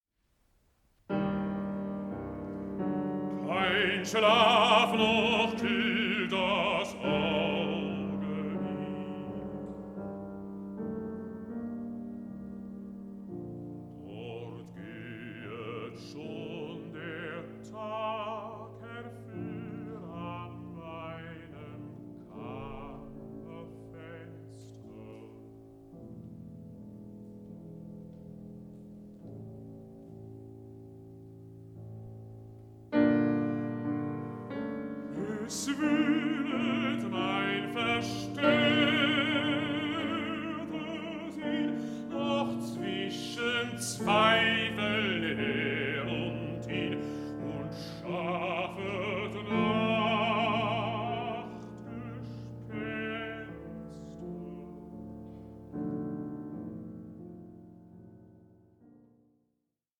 Songs to poems